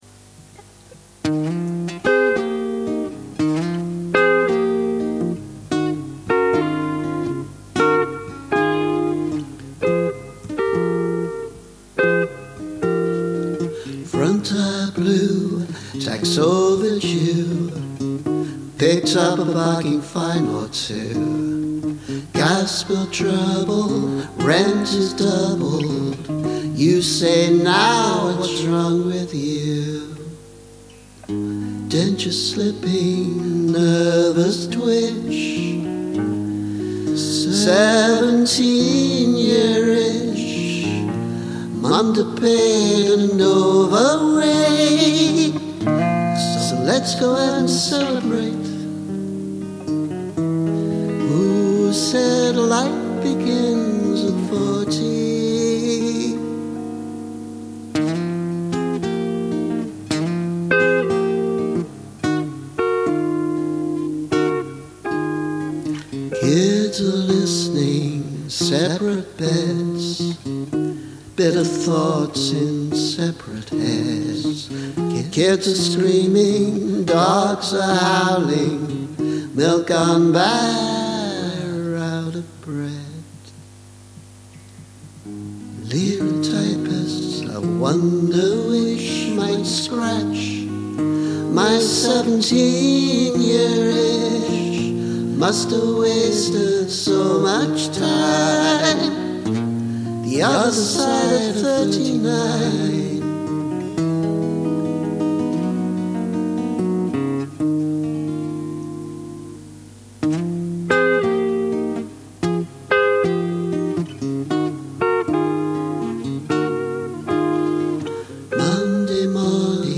17 year itch [demo]